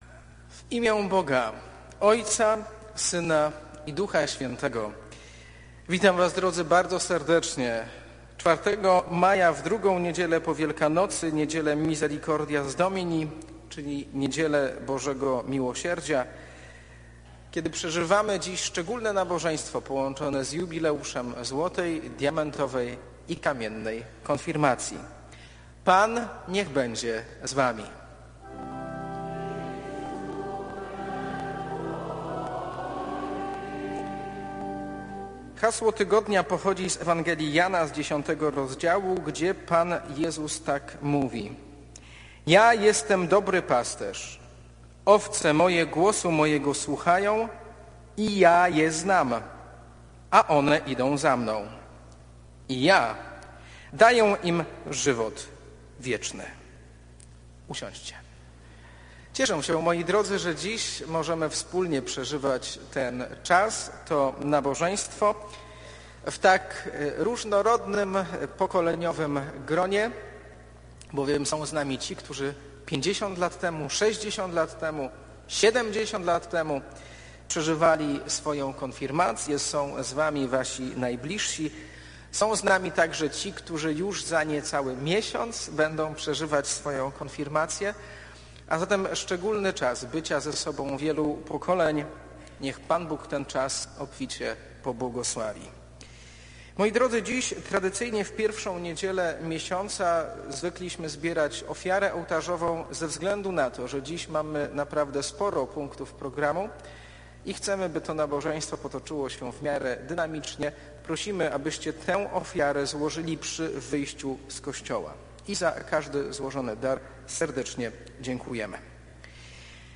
W sobotni wieczór 22 października w naszym kościele miał miejsce koncert
Występujące zespoły zaprezentowały bardzo ciekawy repertuar ,a wykonywane pieśni zrobiły duże wrażenie na licznie zgromadzonej publiczności, czego dowodem były oklaski i pieśni wykonane na bis przez oba chóry.